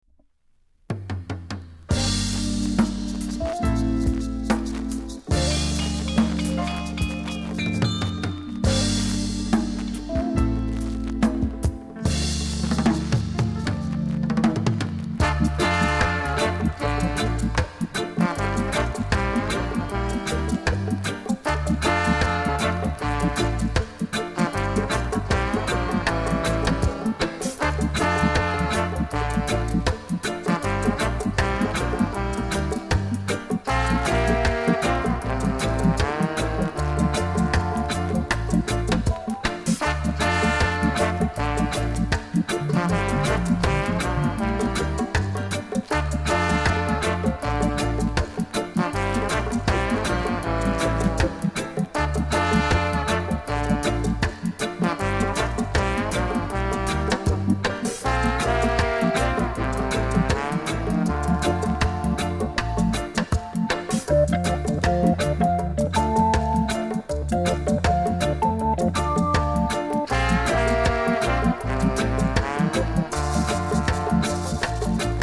※盤は概ねキレイですが、出だしにプレスに起因するジリジリノイズがあります。
DEEP INST!!